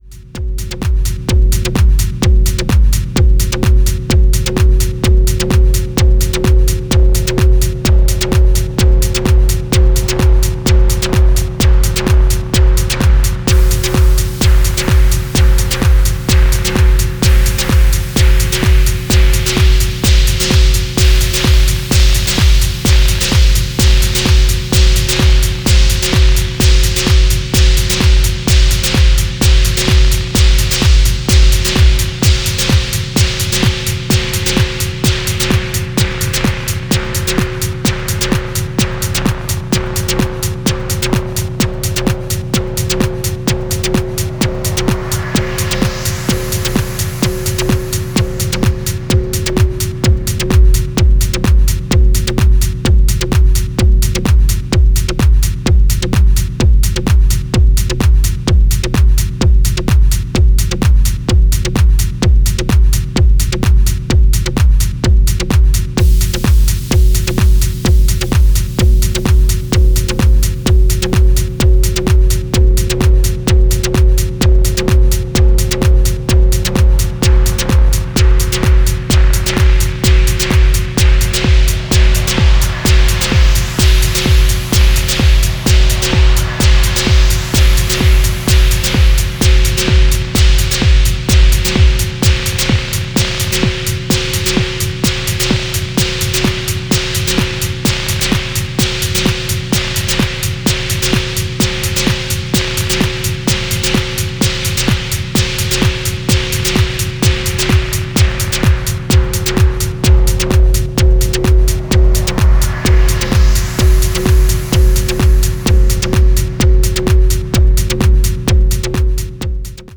Refined techno action times two, but what did you expect?